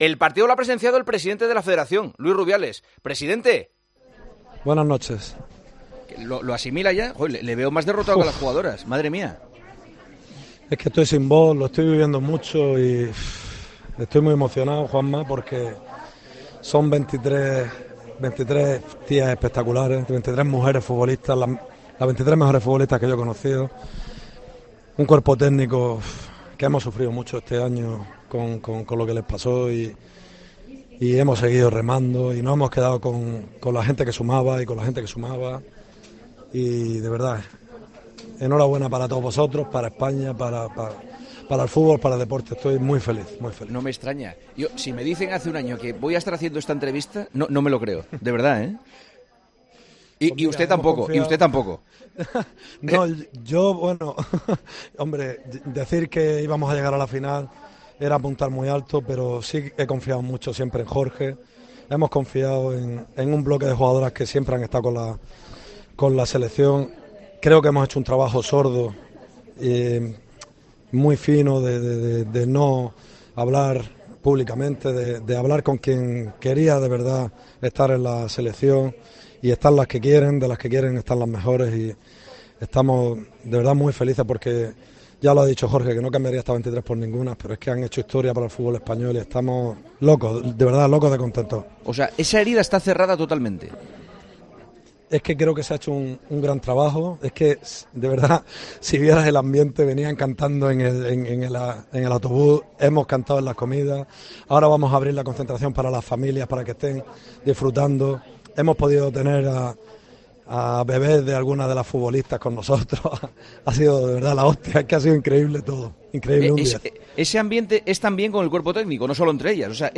Durante el programa, Juanma Castaño ha podido hablar con el presidente de la Federación Española, Luis Rubiales.